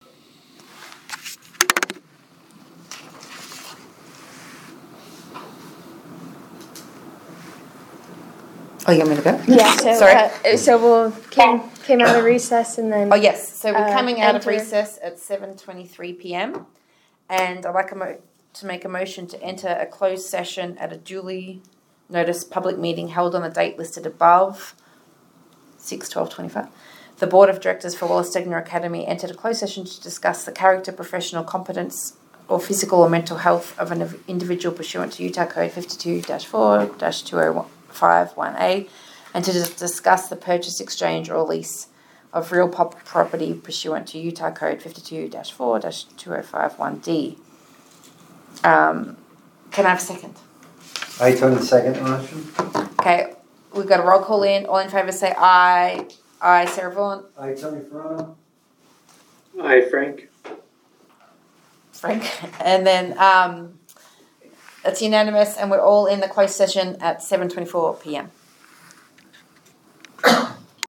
Meeting
One or more board members may participate electronically or telephonically pursuant to UCA 52-4-207.